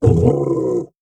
MONSTER_Growl_Medium_15_mono.wav